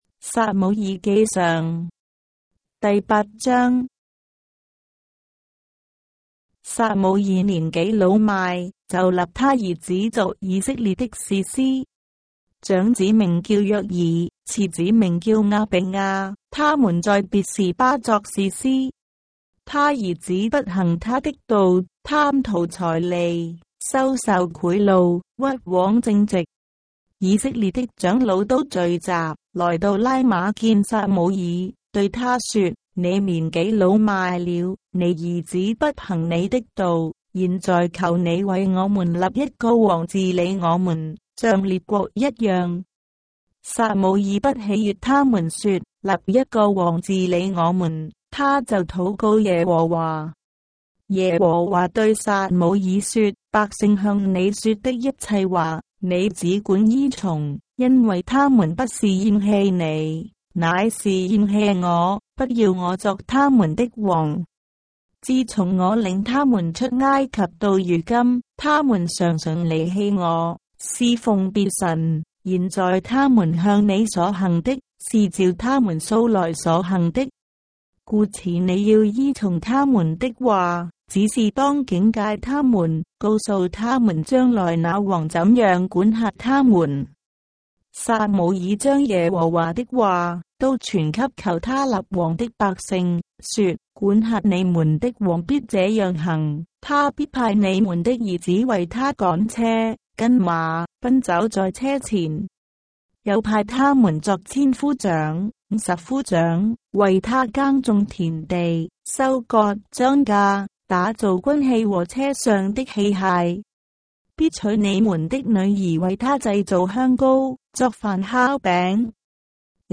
章的聖經在中國的語言，音頻旁白- 1 Samuel, chapter 8 of the Holy Bible in Traditional Chinese